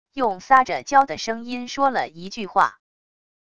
用撒着娇的声音说了一句话wav音频